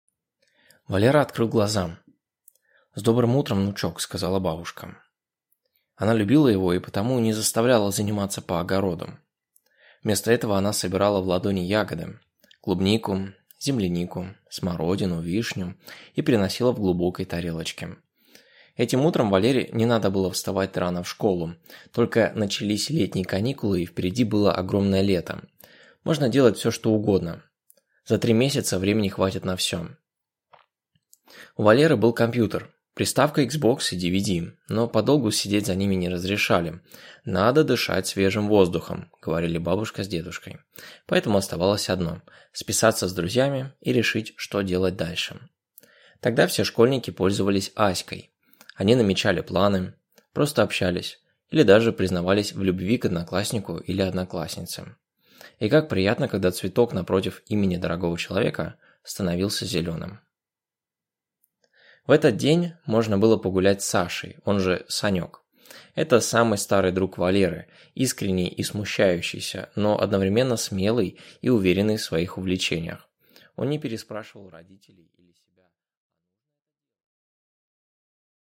Аудиокнига Один день лета в Дятьково | Библиотека аудиокниг